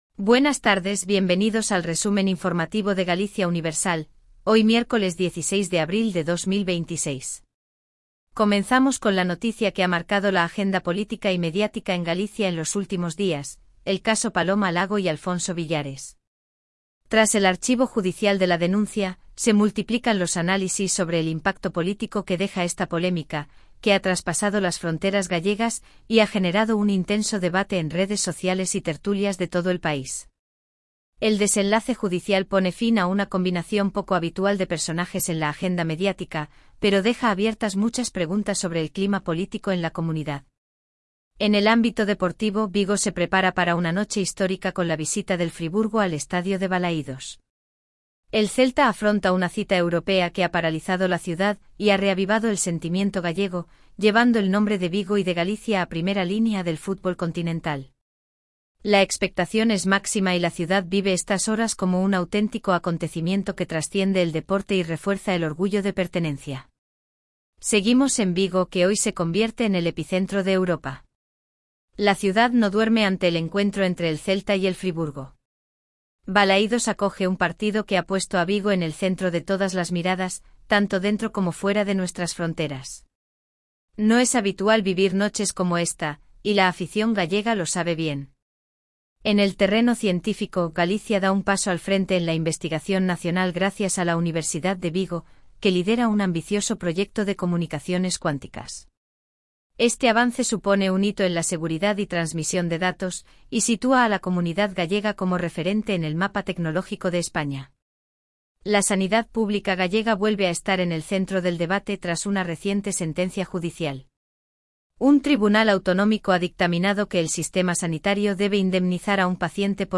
🎙 PODCAST DIARIO